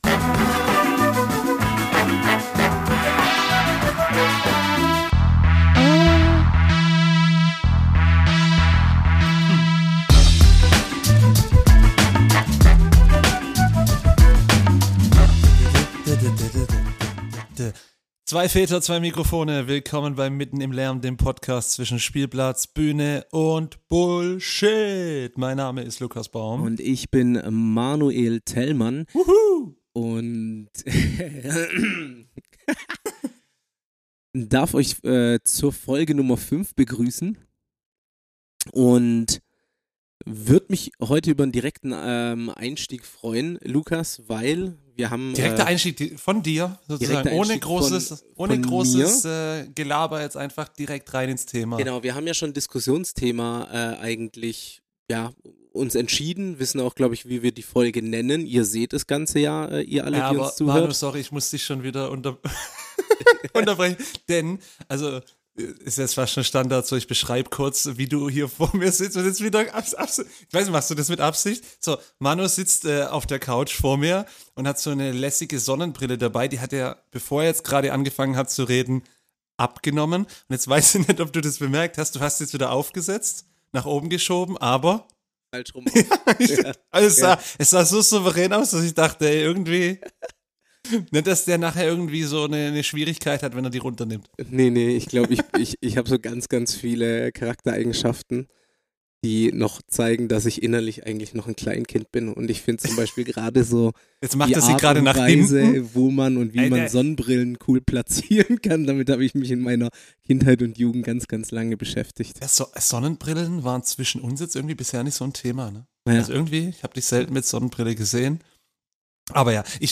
Doch auch ihre gemeinsamen Leidenschaften – Musik und Sport – kommen nicht zu kurz. Freut euch auf ein ehrliches, unterhaltsames und vielseitiges Gespräch!